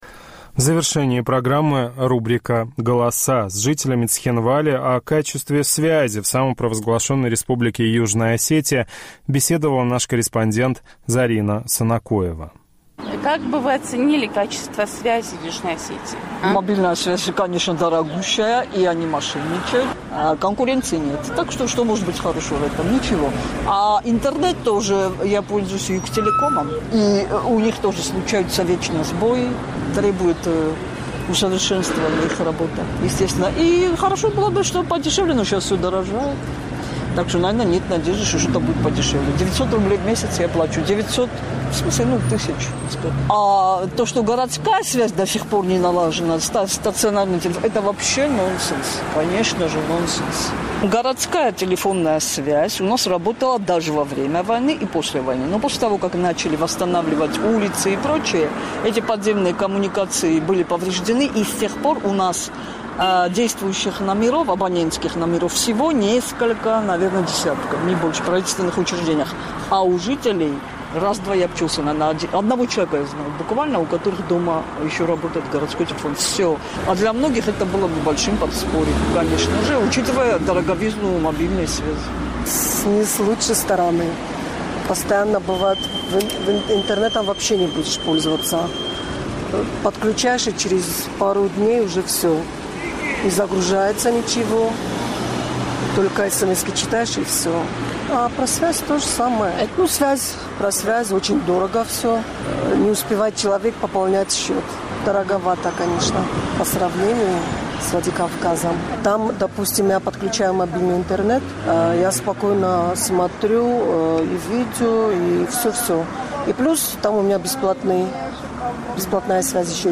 По сравнению с Владикавказом, связь в Южной Осетии дорогая и некачественная, стационарной телефонной связи почти не осталось. Об этом нам рассказали участники нашего традиционного цхинвальского опроса.